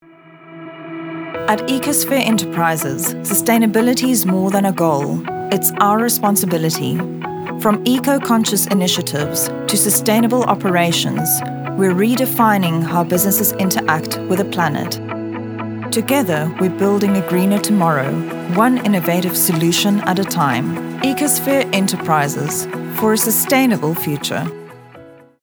authoritative, dramatic, elegant, soothing
Elegant, Sophisticated, and sometimes, just a little bit silly.
Corporate Voice Over